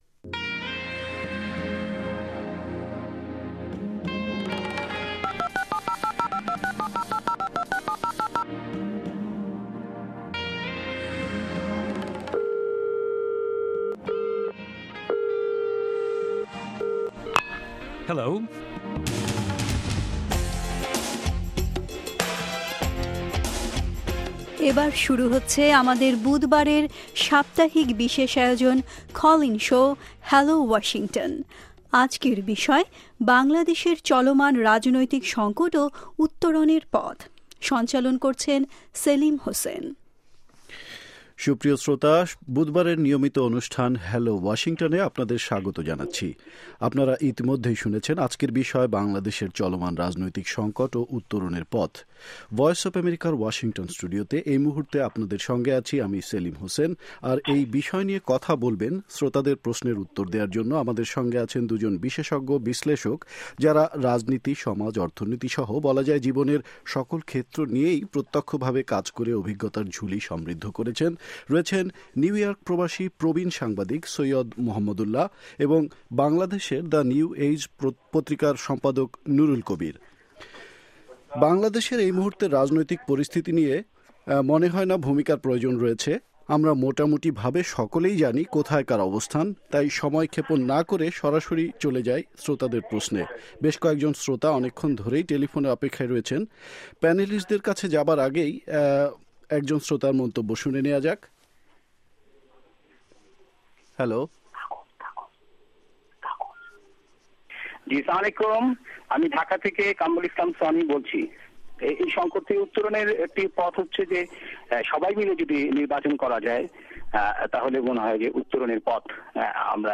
সুপ্রিয় স্রোতা বুধবারের নিয়মিত অনুষ্ঠান হ্যালো ওয়াশিংটনে আপনাদের স্বাগত জানাচ্ছি। আপনারা ইতিমধ্যেই শুনেছেন আজকের বিষয়: বাংলাদেশের চলমান রাজনৈতিক সংকট ও উত্তোরণের পথ। ভয়েস অব আমেরিকার ওয়াশিংটন ষ্টুডিওতে